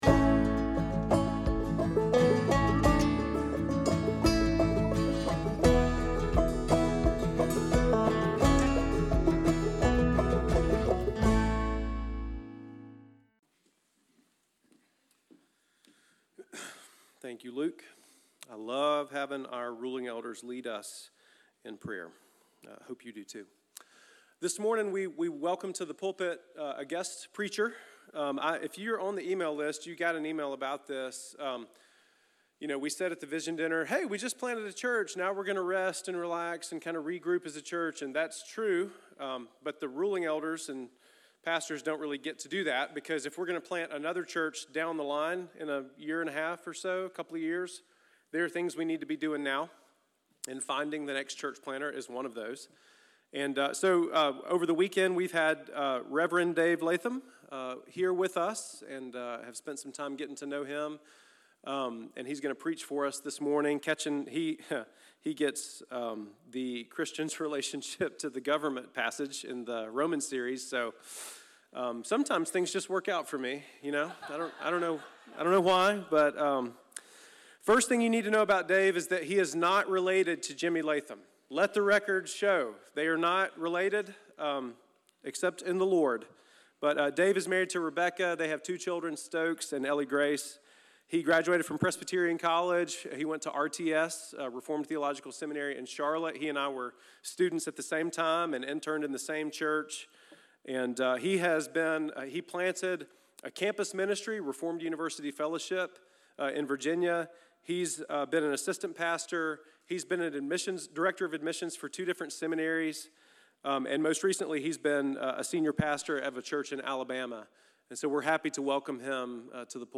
Sermon-for-March-29th.mp3